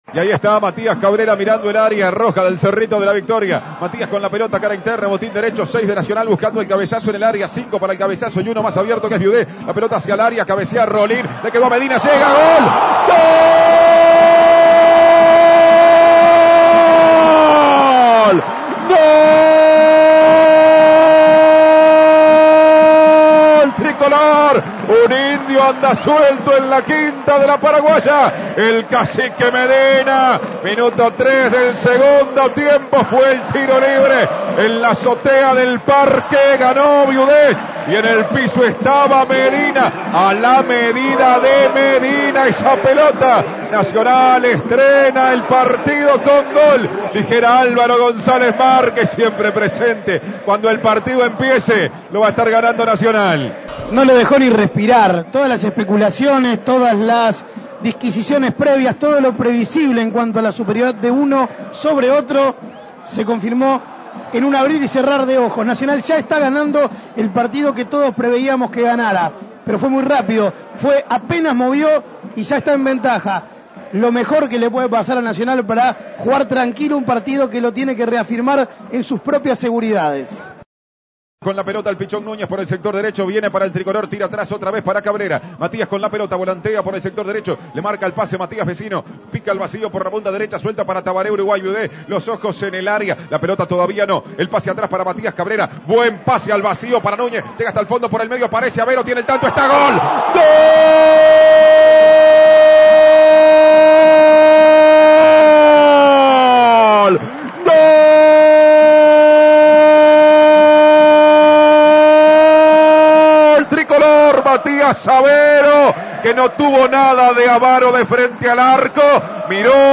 Escuche los goles del partido